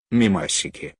PLAY AUGHHHHH… AUGHHHHH
Play, download and share Gh original sound button!!!!